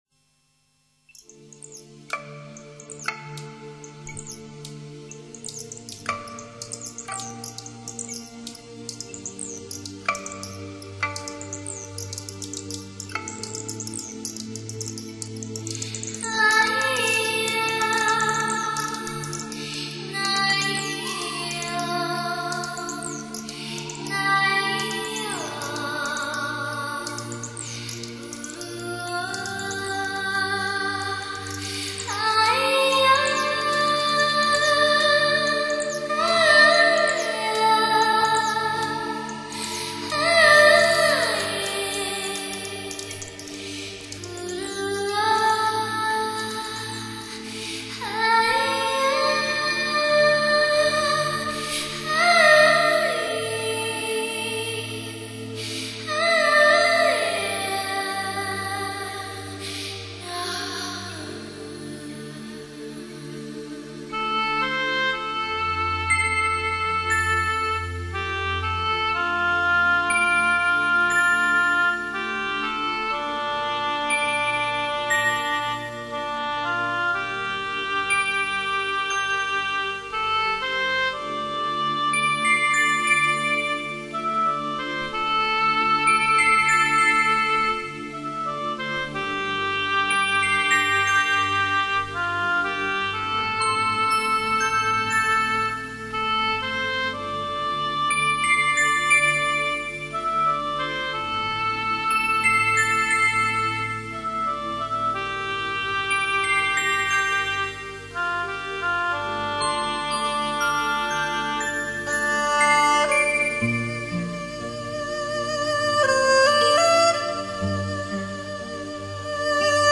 采用现代编曲手法
旋律清幽抒情